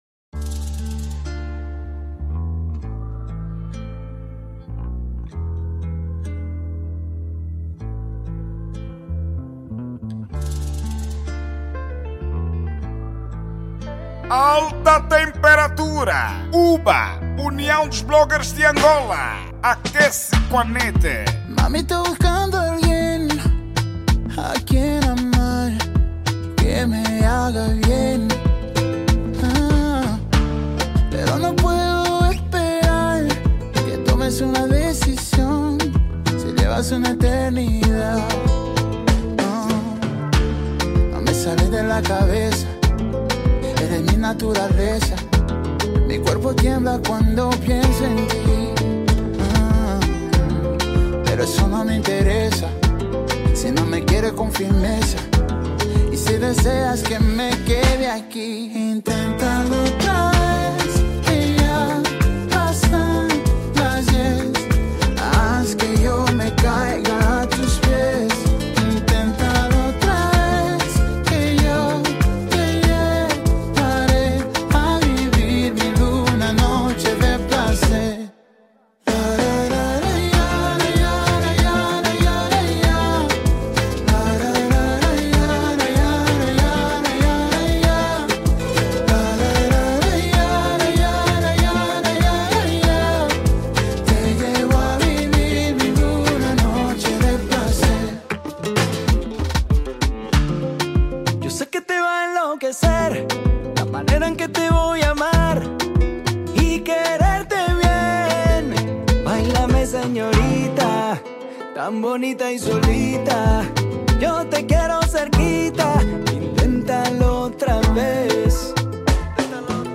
Gênero: Dance Hall